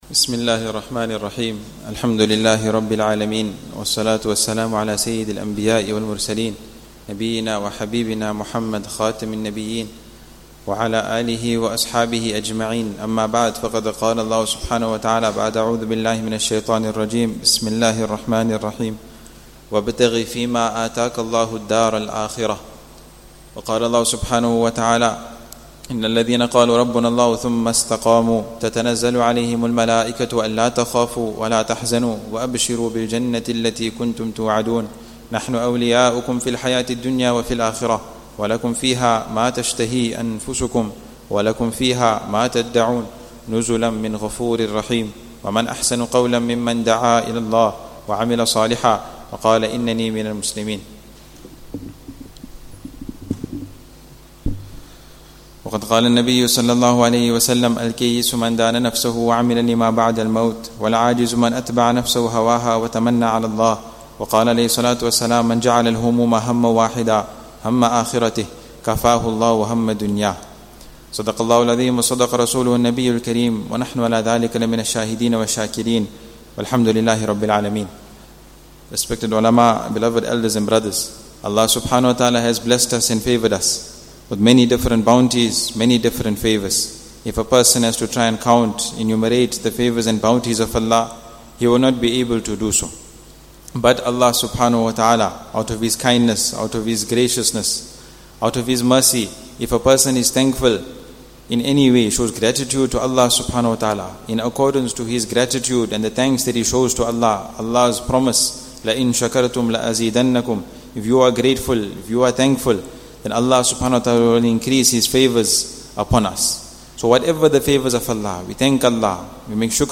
After Fajr Bayaan